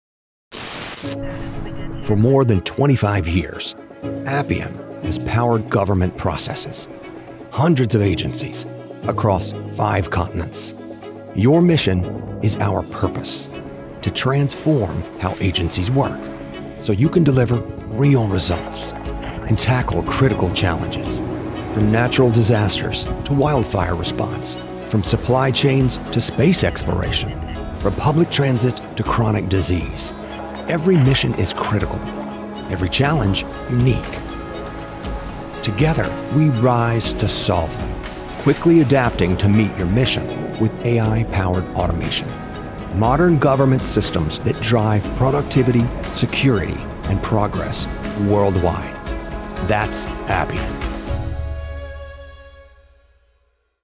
Corporate Brand Narration – Appian Tech - Confident, Inspiring, Authoritative, Leader
Middle Aged